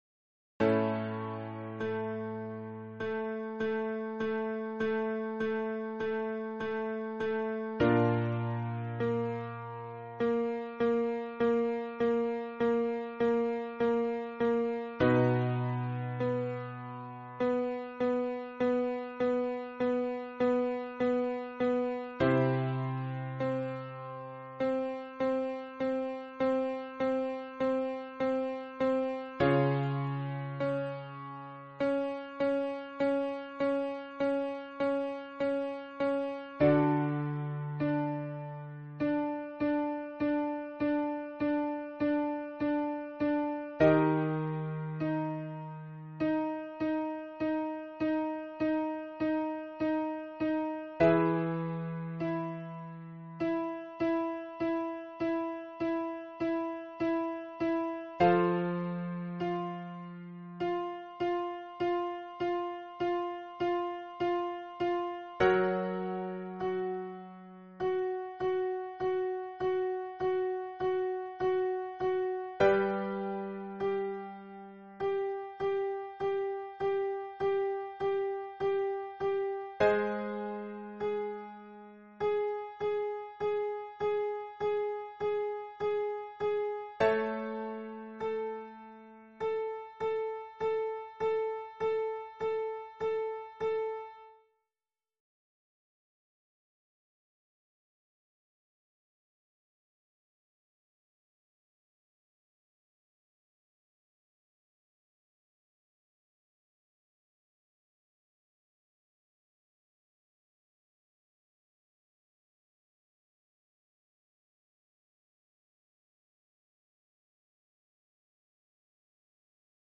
Lesson 2 Audio Exercises